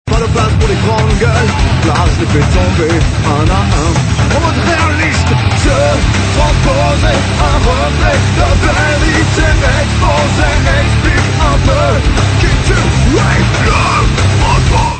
hardcore